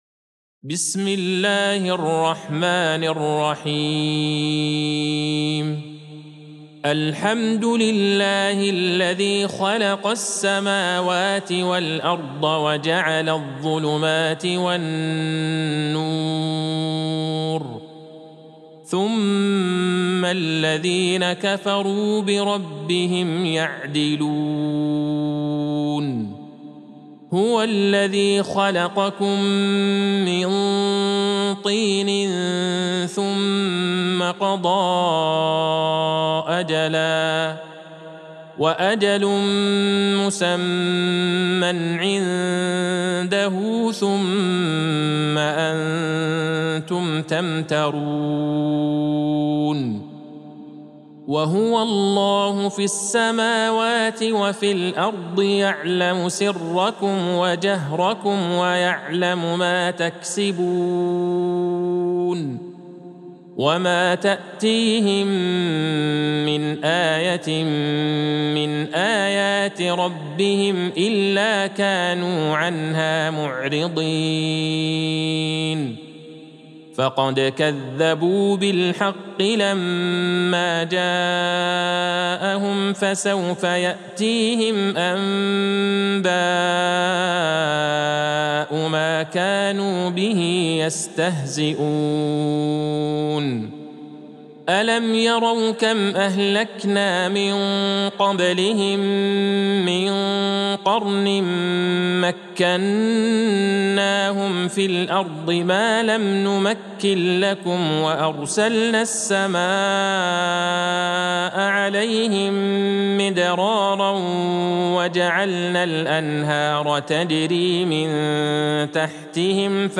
سورة الأنعام Surat Al-An'am | مصحف المقارئ القرآنية > الختمة المرتلة ( مصحف المقارئ القرآنية) للشيخ عبدالله البعيجان > المصحف - تلاوات الحرمين